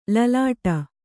♪ lalāṭa